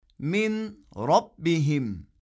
Bu durumda ğunne yapılmaz, ses tutulmaz.
Türkçede “On Lira” için “OLLira” okunması gibidir.